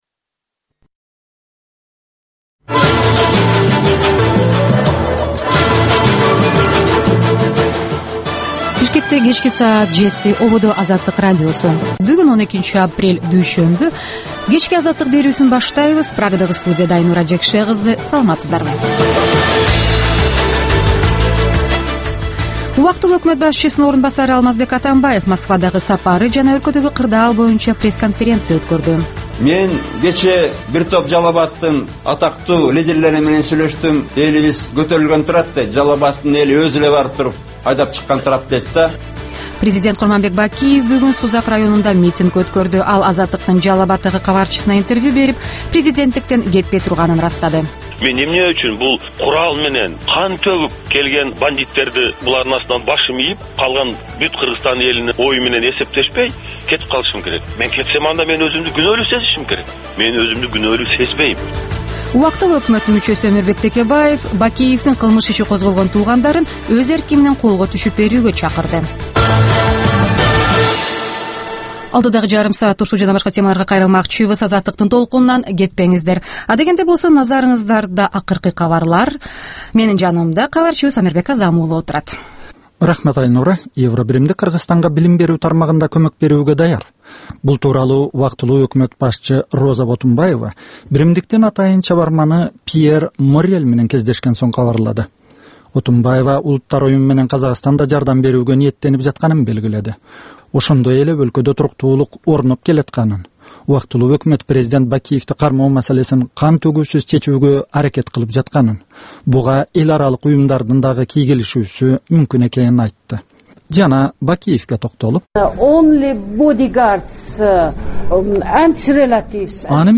"Азаттык үналгысынын" бул кечки алгачкы берүүсү (кайталоо) жергиликтүү жана эл аралык кабарлардан, репортаж, маек, баян жана башка берүүлөрдөн турат. Бул үналгы берүү ар күнү Бишкек убактысы боюнча кечки саат 19:00дан 19:30га чейин обого чыгат.